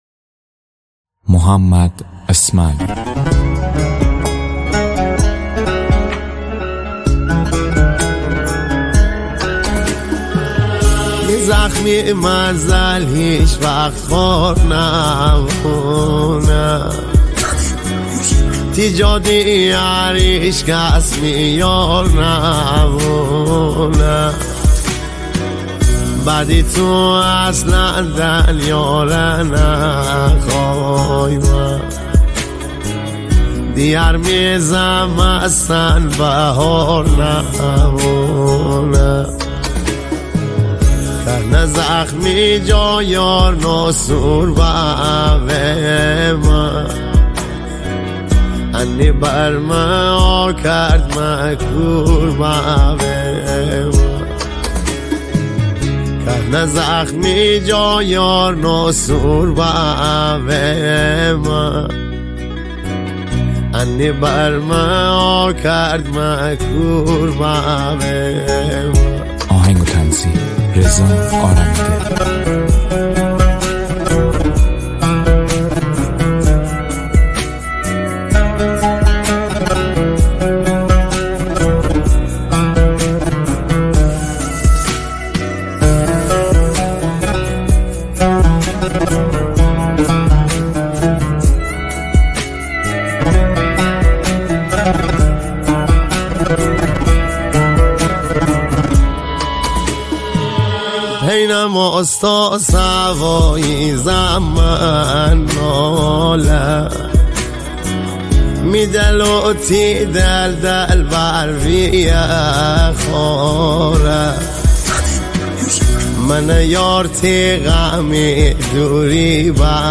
ریتمیک ( تکدست )
غمگین